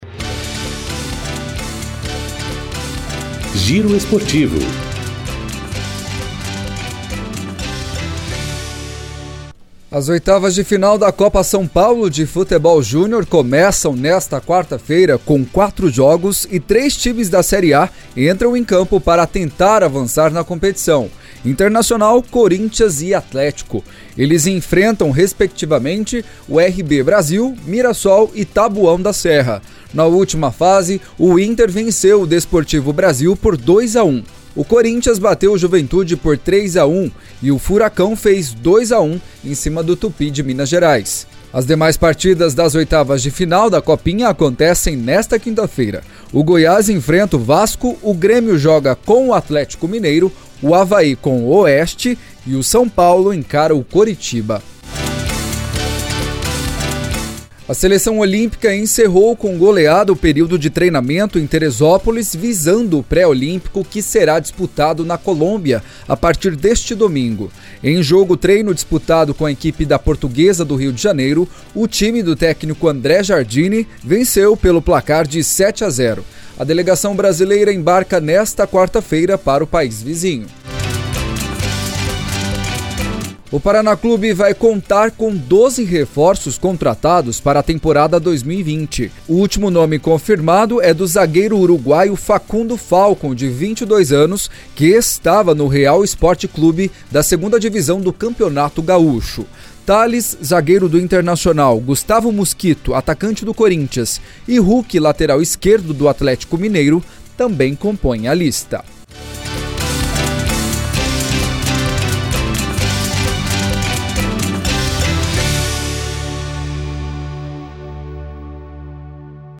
Giro Esportivo COM TRILHA